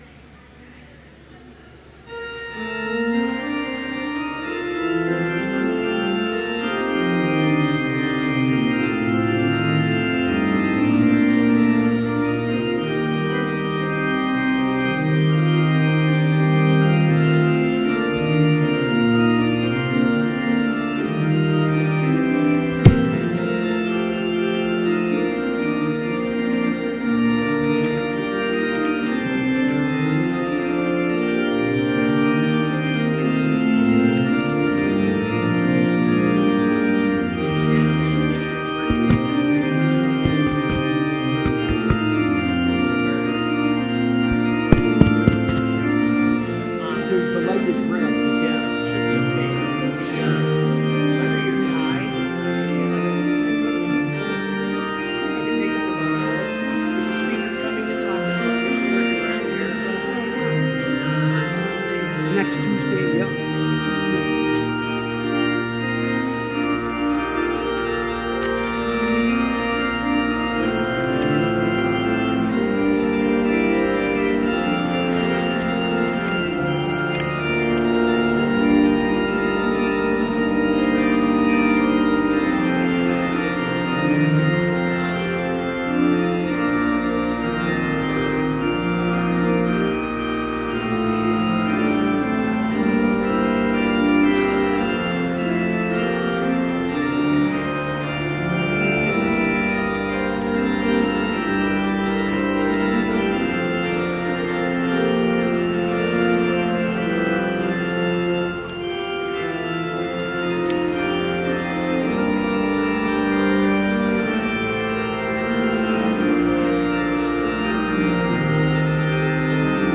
Complete service audio for Chapel - September 28, 2017